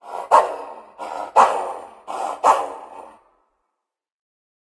angry_loop.ogg